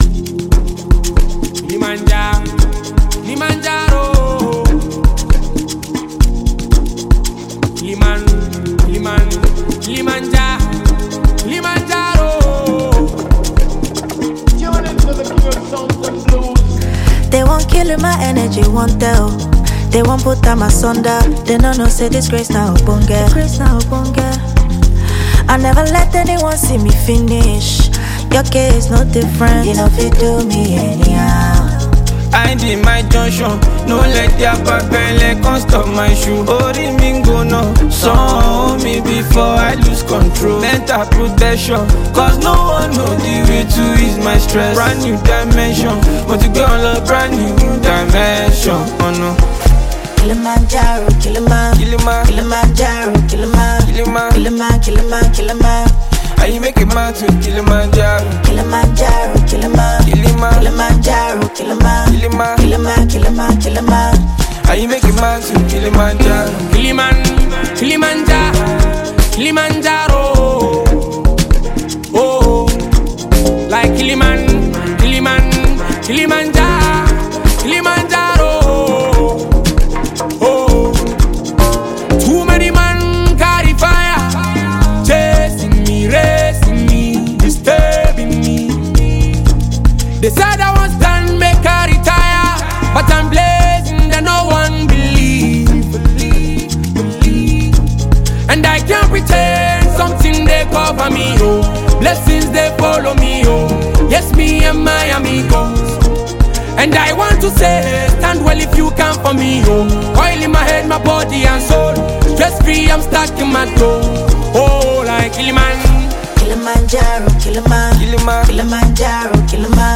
sweet-sounding tune
Famous Nigerian singer and songwriter